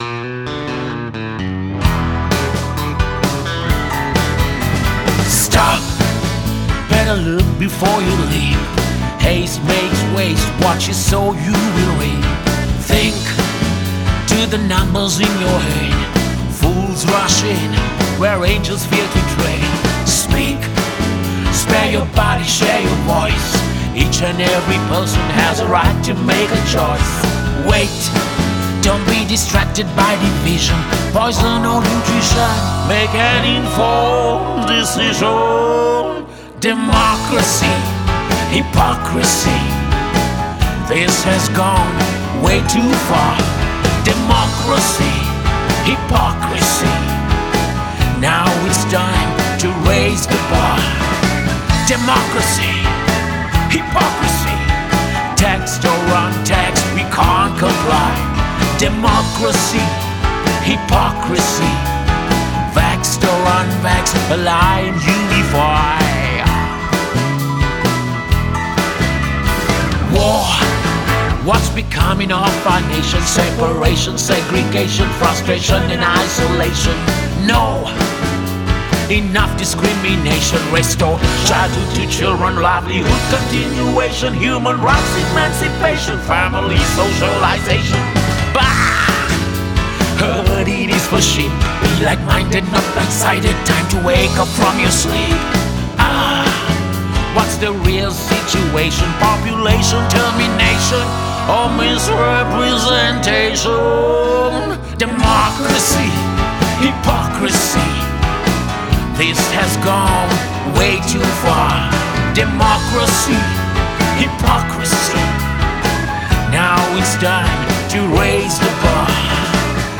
vocals and harmonies are cheerful and uplifting